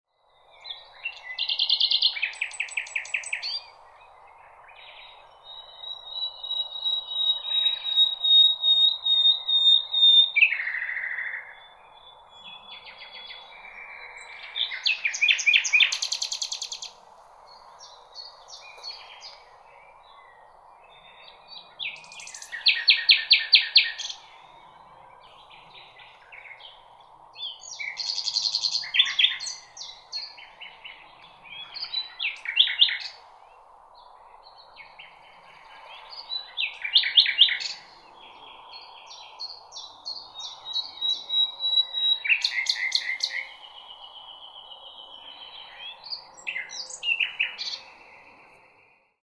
Spring Bird Song Sound Effect
Beautiful bird singing in spring in a natural setting – Early spring nature ambience. Bird sounds.
Spring-bird-song-sound-effect.mp3